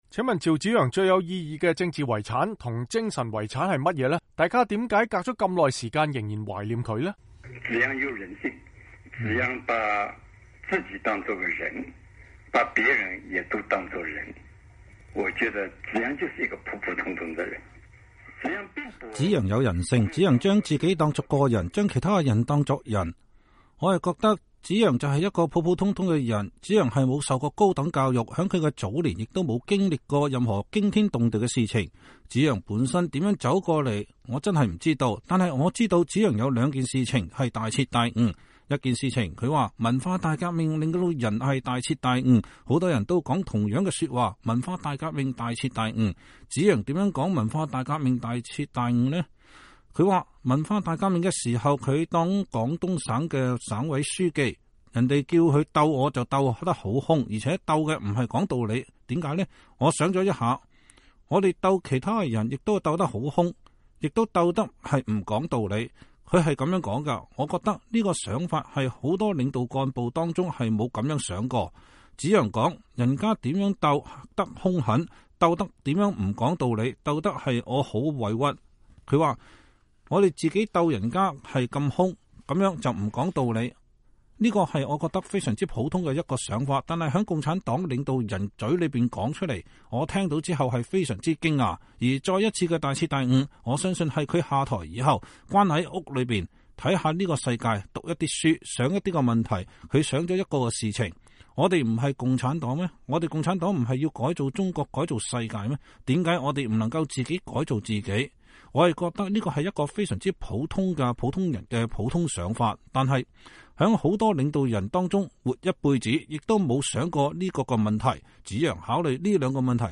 原中共中央委員、趙紫陽政治秘書鮑彤
（根據採訪電話錄音整理，受訪者觀點不代表美國之音）